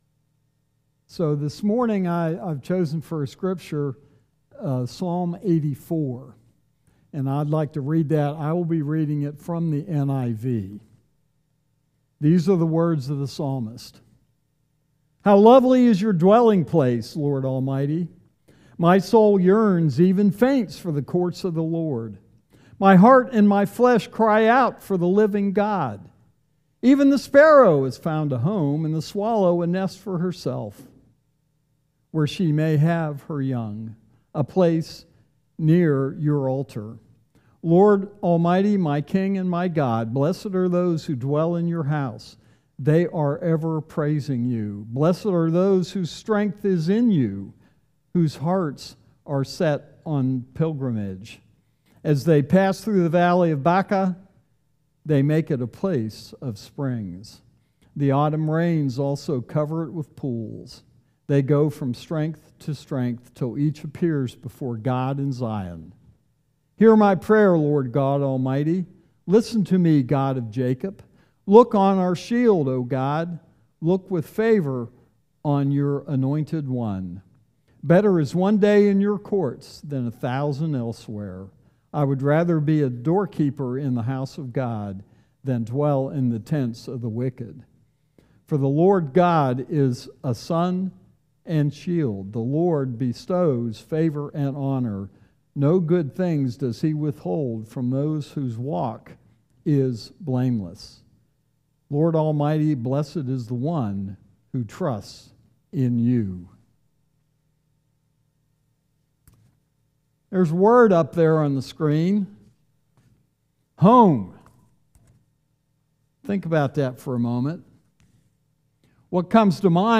Sermons | Mechanic Grove Church of the Brethren